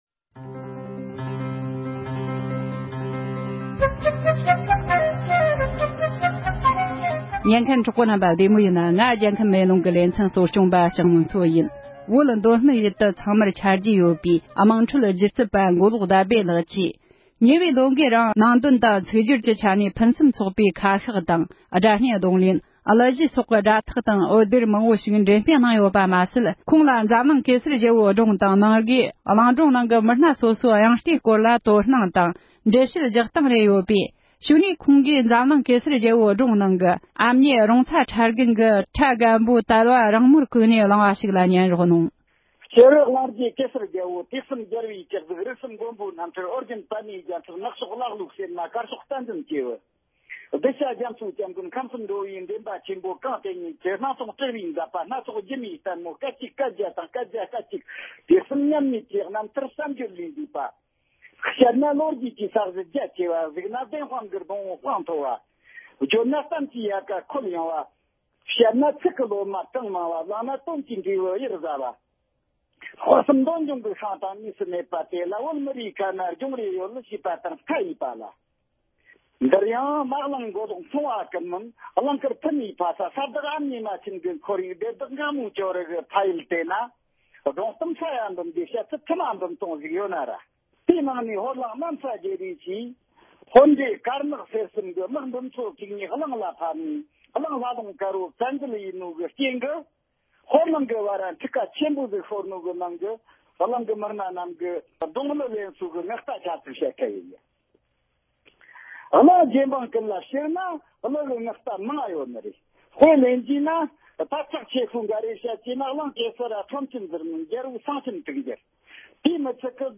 གླིང་གེ་སར་རྒྱལ་པོའི་སྒྲུང་ནང་གི་དབྱངས་རྟ་སྣ་ཚོགས་ལེན་སྟངས་སྐོར་གླེང་མོལ།